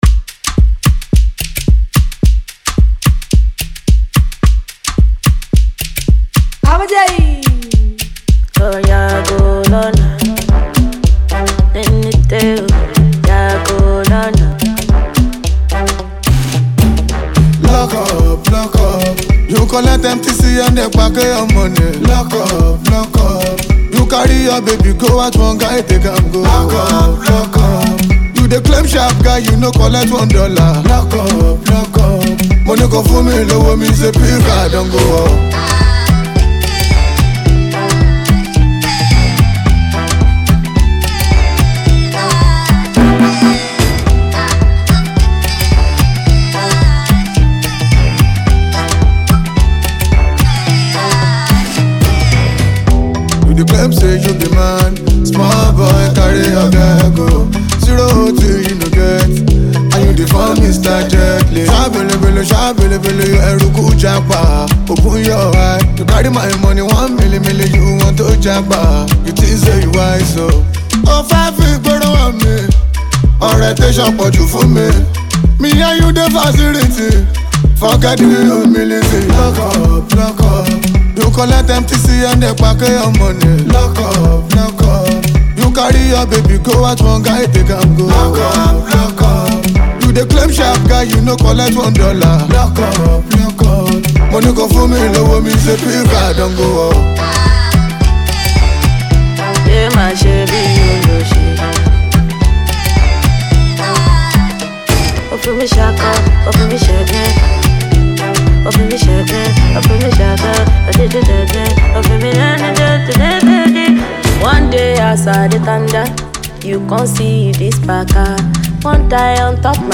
Talented singer
Afro House